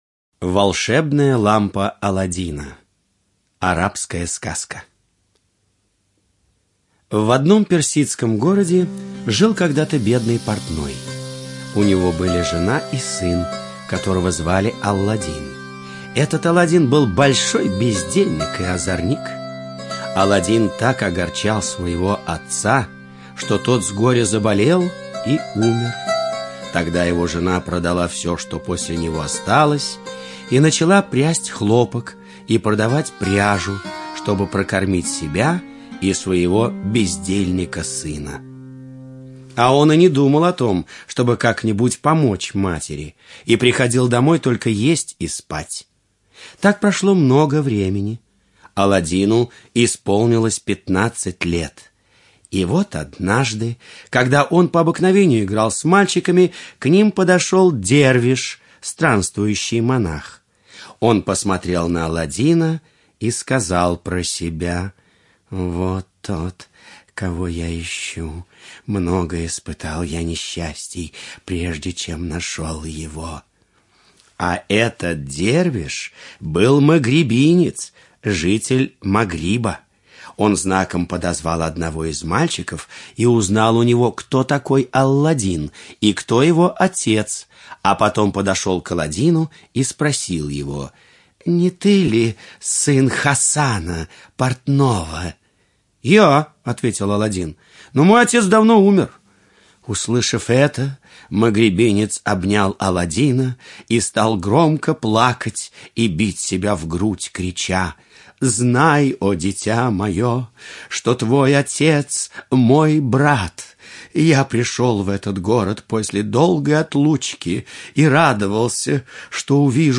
Волшебная лампа Аладдина - арабская аудиосказка - слушать онлайн